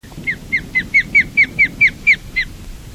Rybołów - Pandion haliaetus
głosy